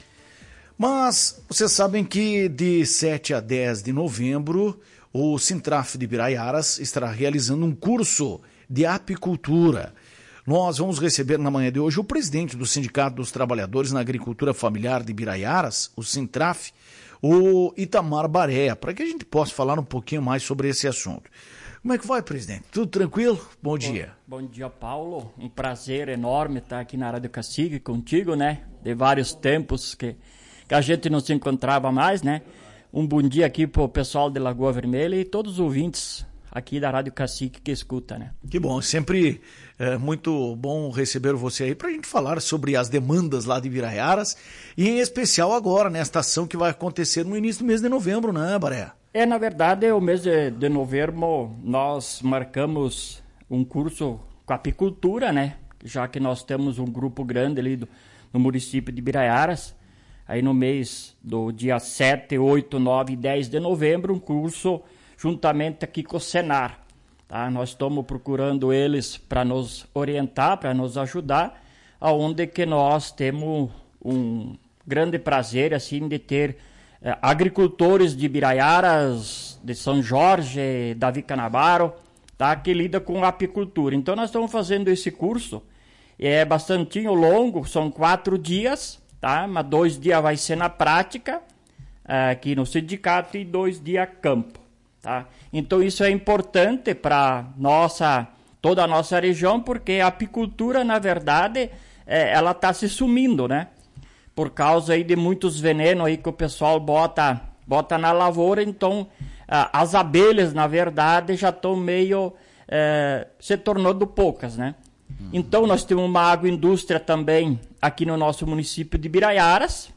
anunciou em entrevista à Tua Rádio Cacique um curso de apicultura a ser realizado no mês de novembro.